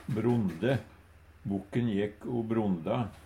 Tilleggsopplysningar brond - brunst brondbokk/brondefant - horebukk/horefant brondegeit - geit "som flyg" i brunst Høyr på uttala Ordklasse: Verb Kategori: Dyreriket Attende til søk